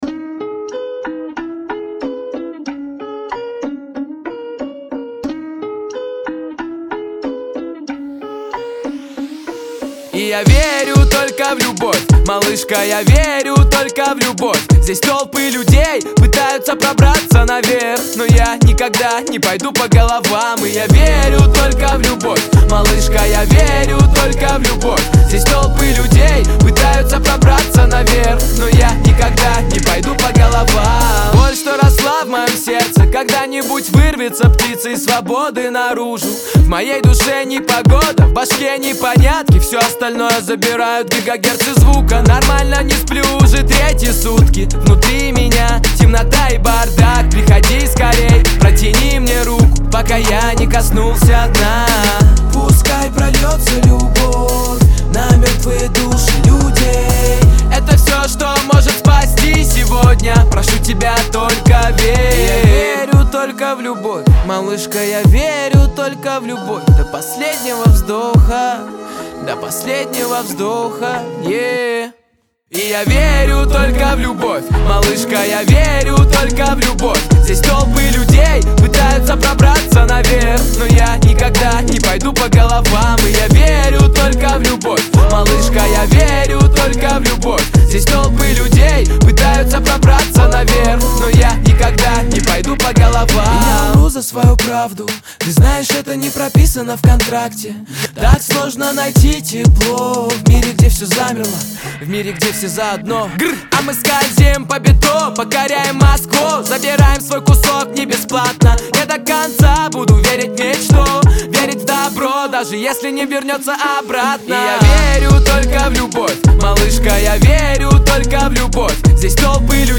который сочетает в себе элементы хип-хопа и поп-музыки.